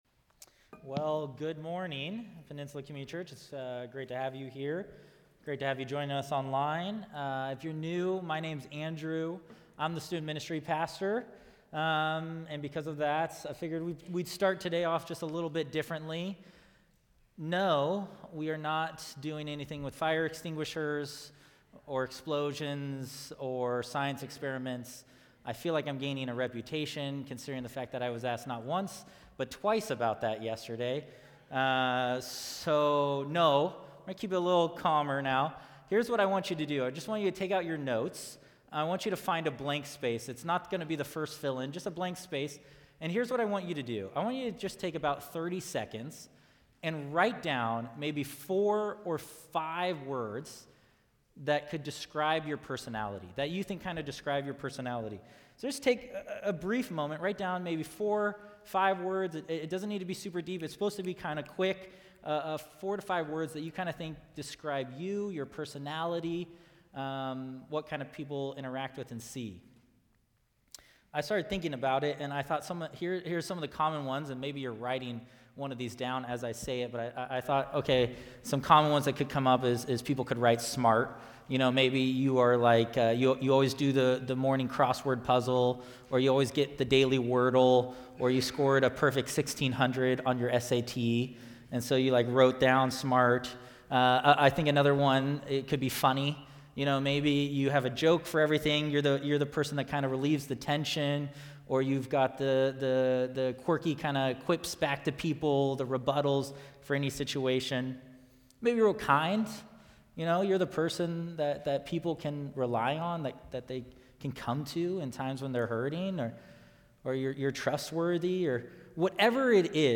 Sermon Archive